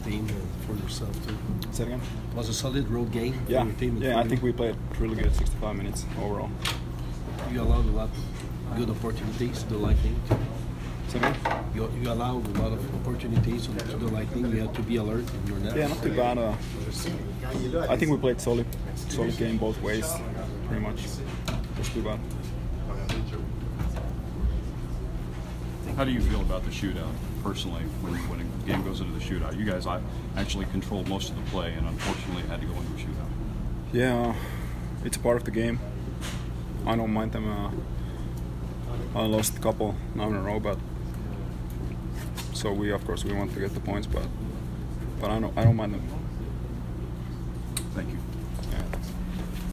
Antti Niemi post-game 3/10